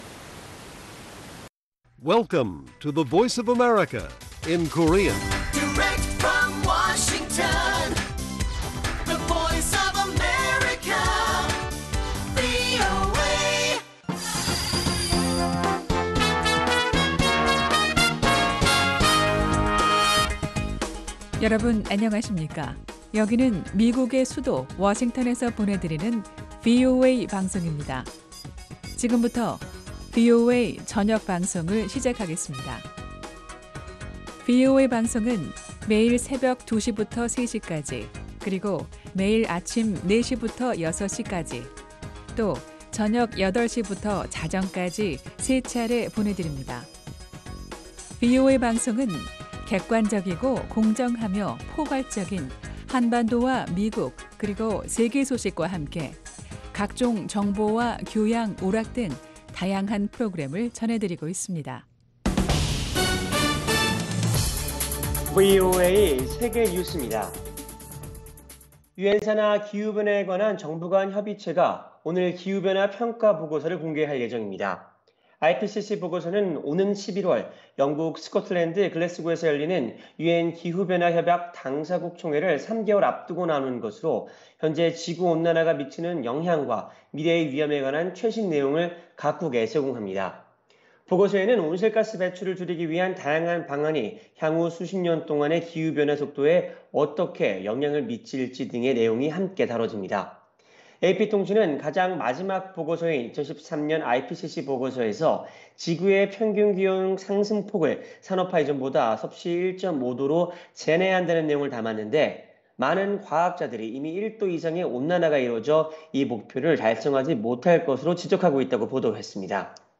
VOA 한국어 간판 뉴스 프로그램 '뉴스 투데이', 2021년 8월 9일 1부 방송입니다. 북한이 올해 상반기에도 계속 핵무기와 탄도미사일 기술을 개발한 것으로 유엔이 평가했습니다. 미국과 한국의 외교 수장들이 한반도에 대한 인도주의적 계획을 모색하기로 합의함에 따라 인도적 위기를 겪고 있는 북한에 지원이 이뤄질 지 관심이 모아지고 있습니다. 미국 상원에 랜섬웨어 공격을 돕는 해외 국가에 제재를 부과하도록 하는 내용의 법안이 발의됐습니다.